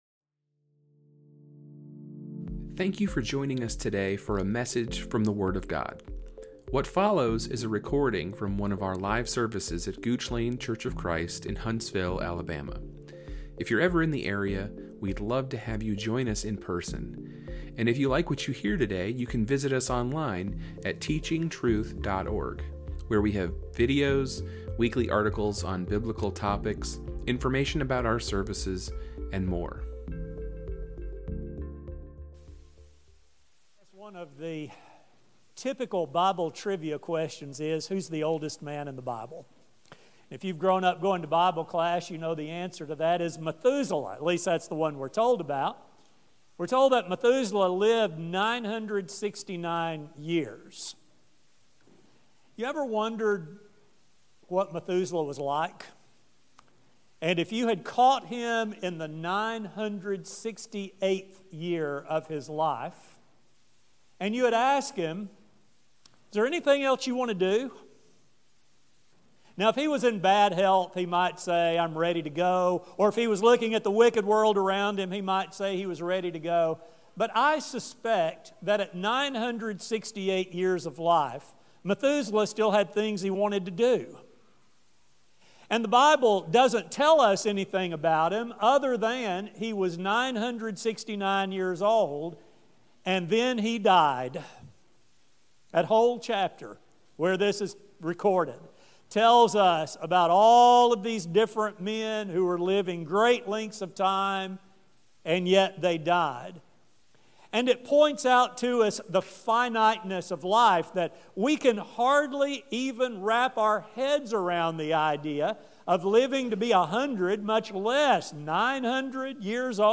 This study will focus on how finitude helps us to better understand God and also how it aids us in a realistic view of life now in comparison with the eternal life to come. A sermon